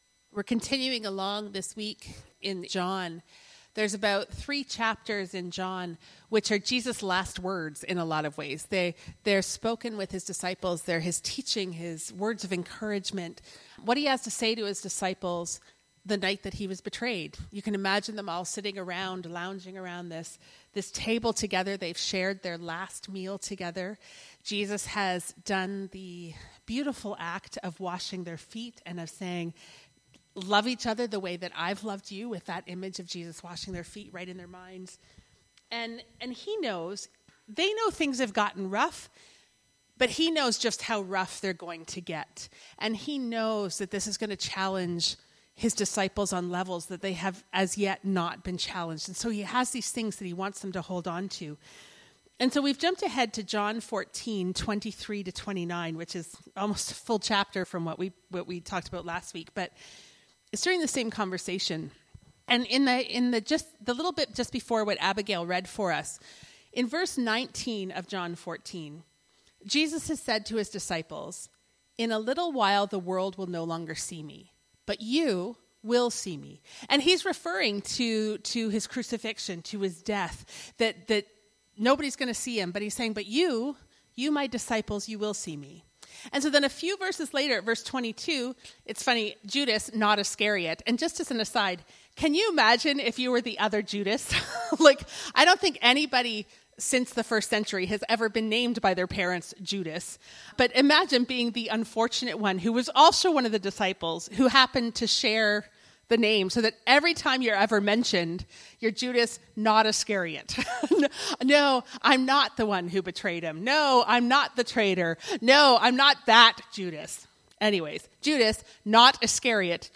There is no Looking to Sunday for this sermon.